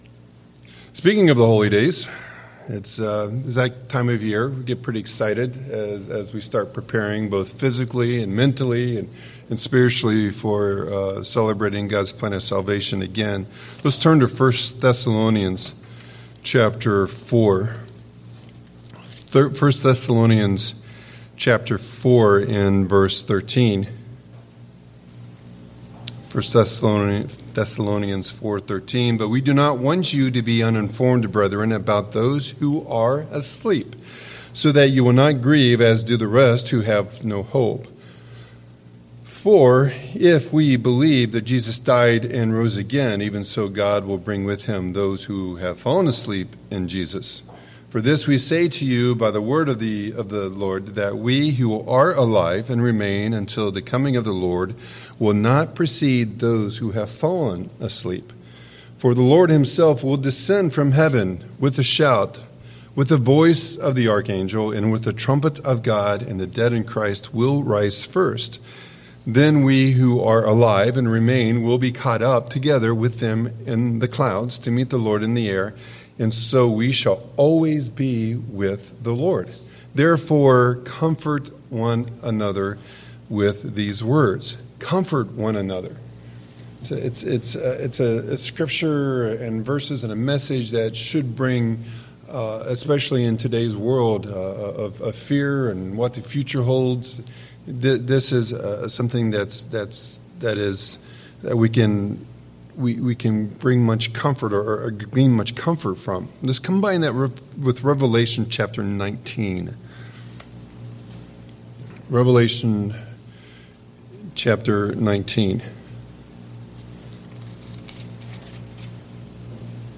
In this sermon, we dive deep into the symbolic relationship between Jesus Christ as the bridegroom and the Church as His bride, exploring the themes of commitment and sacrifice that bind this relationship. Through key scriptures like 1 Thessalonians 4 and Revelation 19, the message examines Christ's unwavering commitment to His followers, His role as a bridegroom, and how His sacrifice set the ultimate example of love and dedication.
Given in Eau Claire, WI